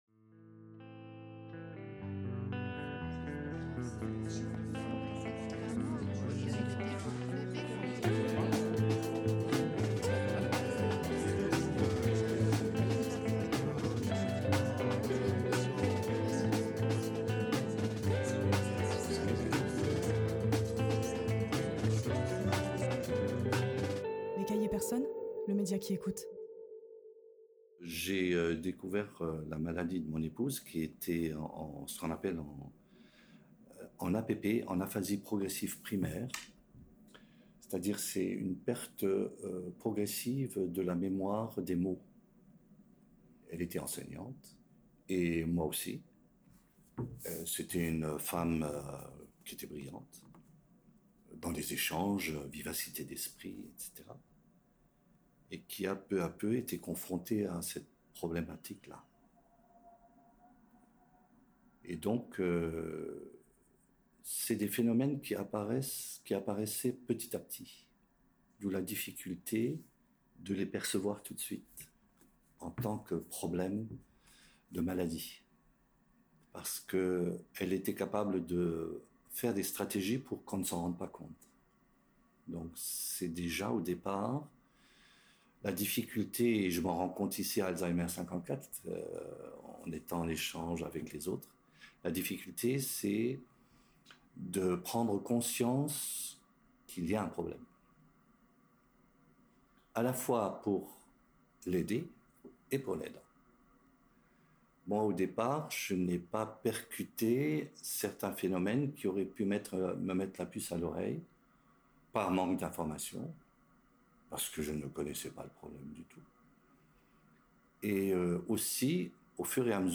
Dans ces podcasts, des femmes, des hommes, se livrent avec leurs voix – uniques, entières. Des voix qui racontent un parcours, des galères, des joies, des éclats de vie.